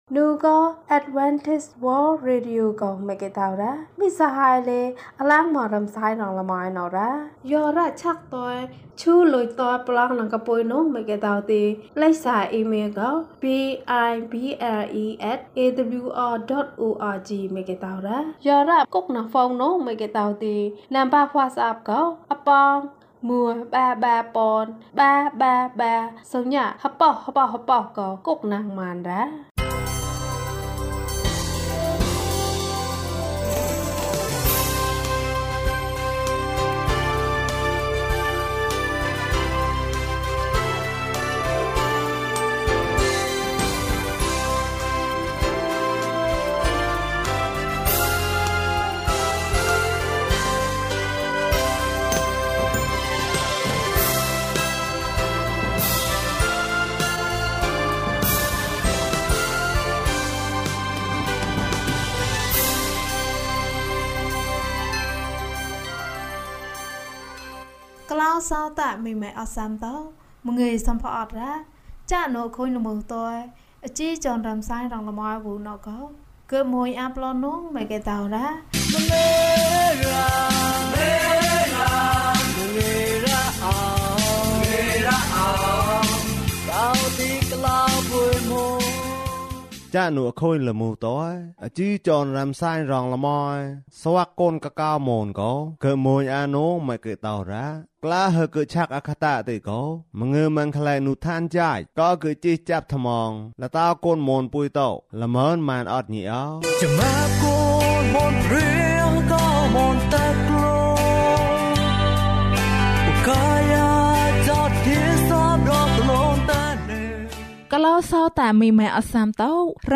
ငါကျဆုံးချိန်မှာ ယေရှု ငါ့ကိုချစ်တယ်။ ကျန်းမာခြင်းအကြောင်းအရာ။ ဓမ္မသီချင်း။ တရားဒေသနာ။